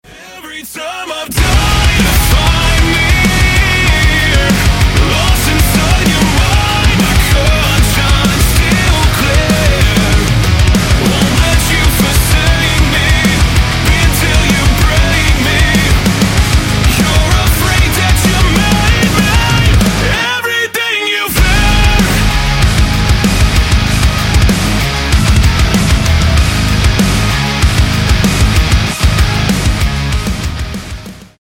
Рок Металл